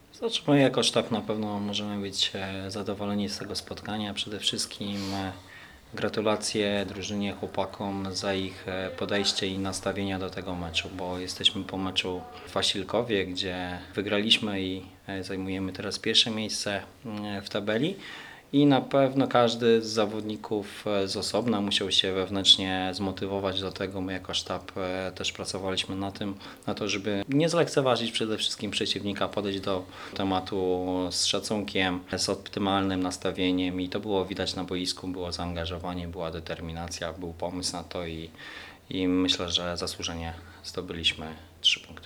konferencja prasowa